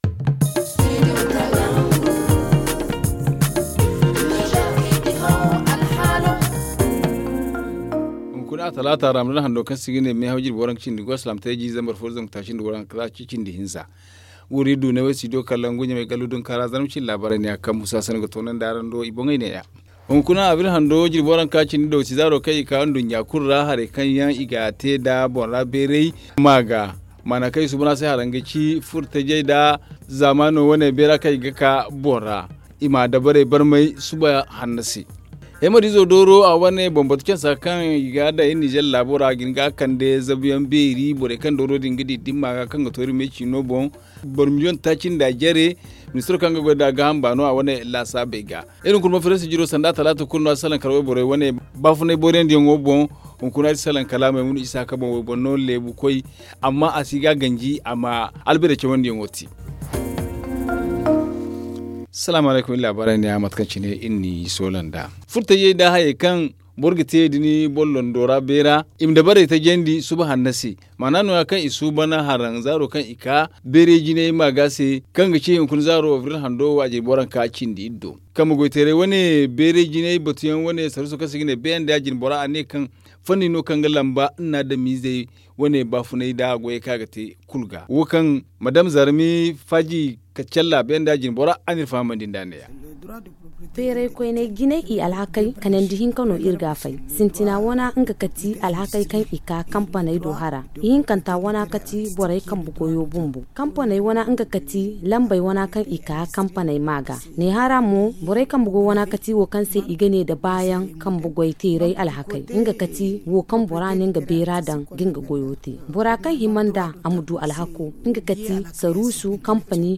Le journal du 26 avril 2022 - Studio Kalangou - Au rythme du Niger